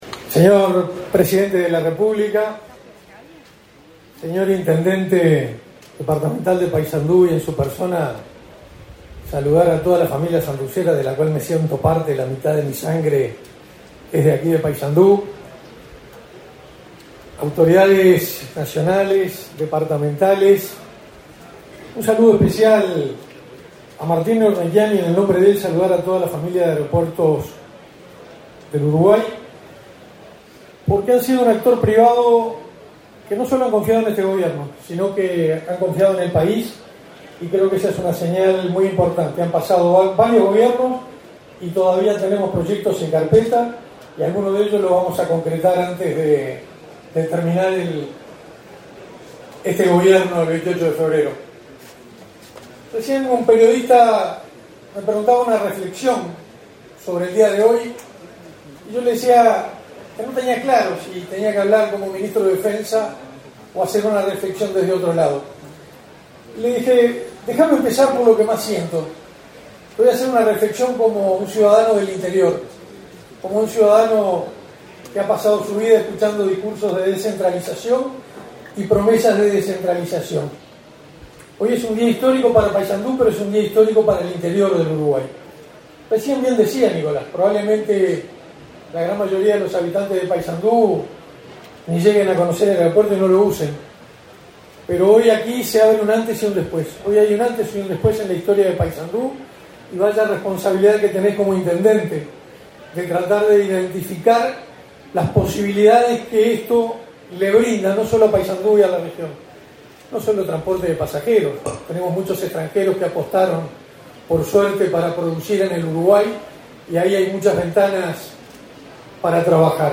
Palabras del ministro de Defensa Nacional, Armando Castaingdebat
En el evento disertó el ministro de Defensa Nacional, Armando Castaingdebat.